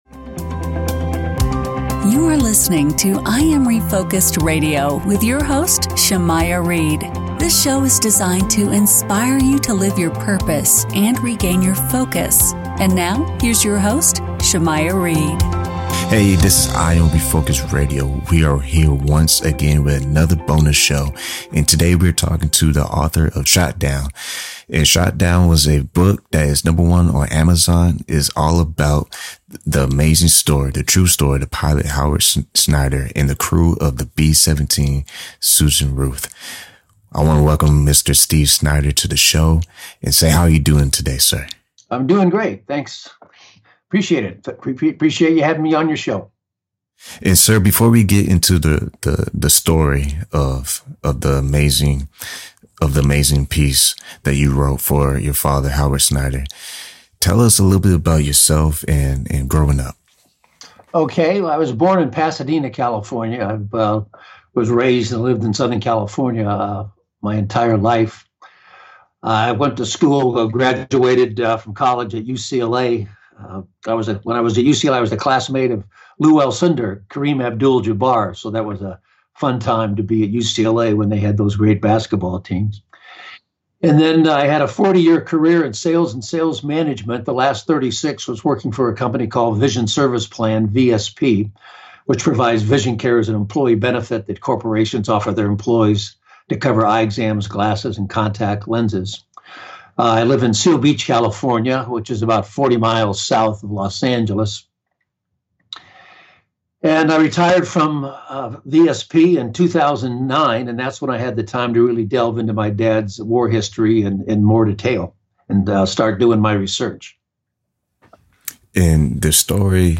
interviewed on I am Refocused Radio